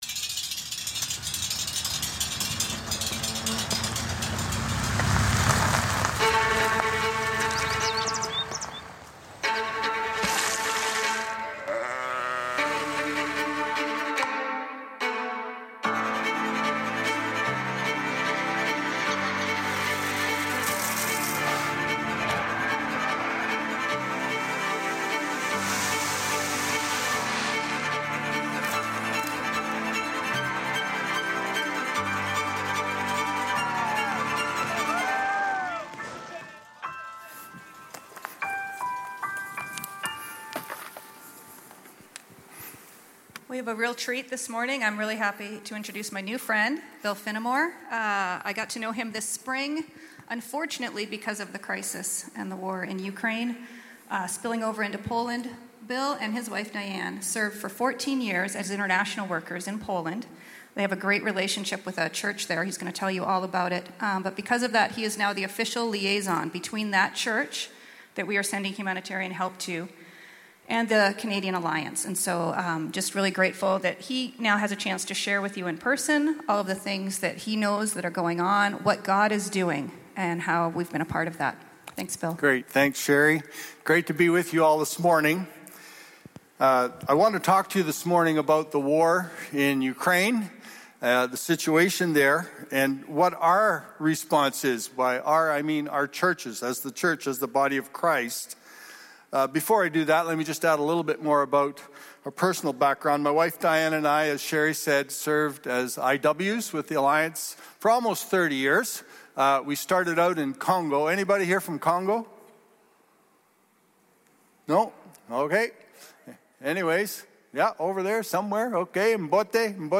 English Teaching MP3 This Weekend's Scriptures...